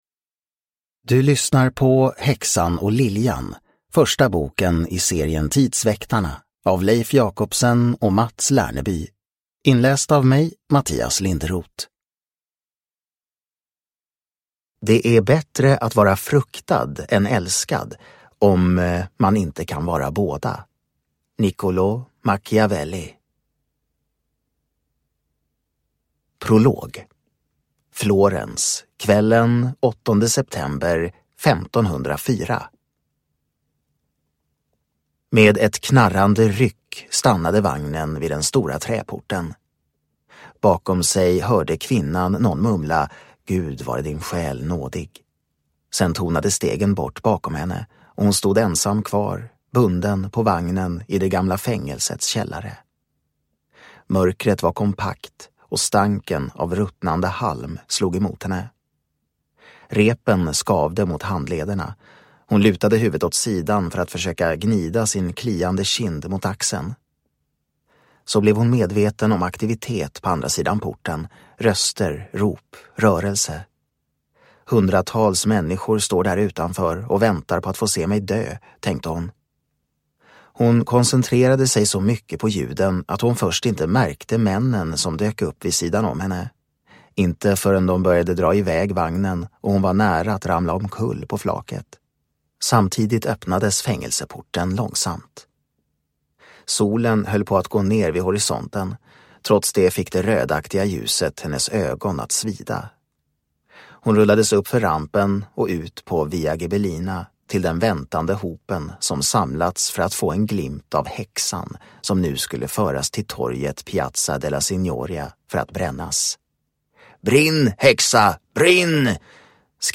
Häxan och liljan (ljudbok) av Mats Lerneby